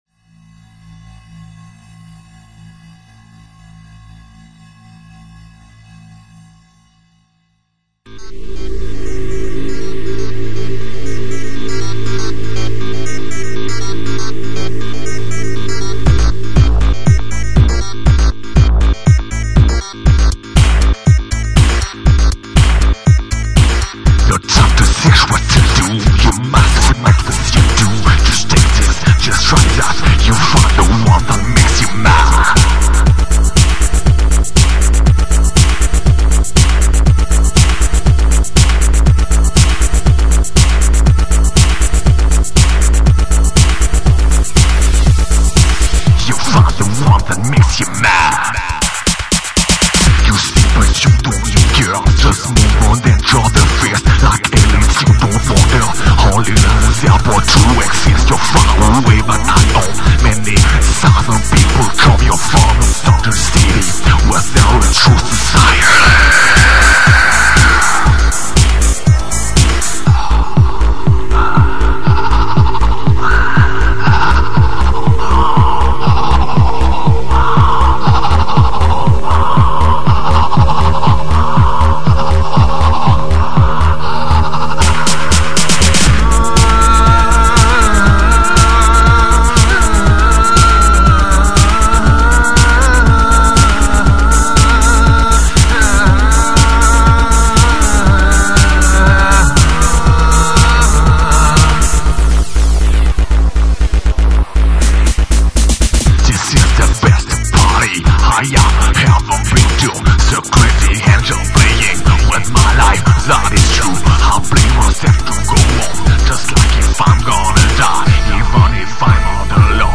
All the following songs/samples have been degraded.